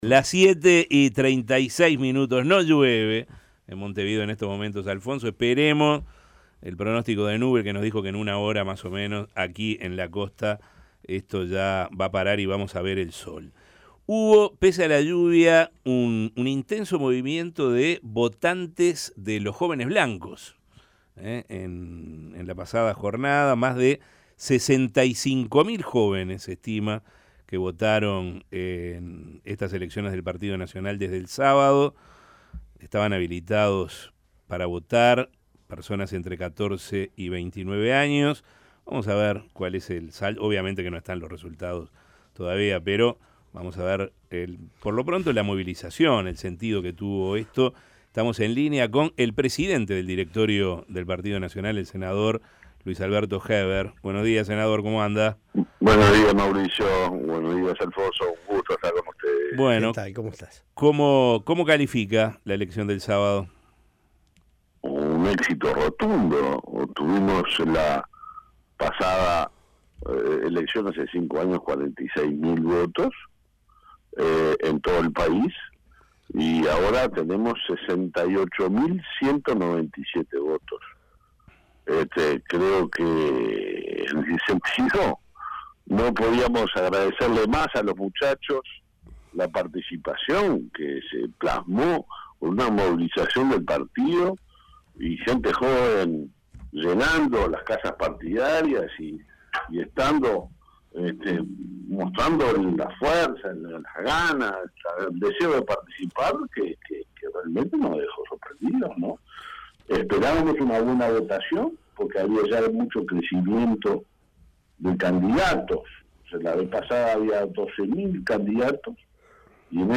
Las elecciones de jóvenes nacionalistas fueron "un éxito rotundo" dijo a La Mañana de El Espectador el presidente del Directorio blanco, Luis Alberto Heber.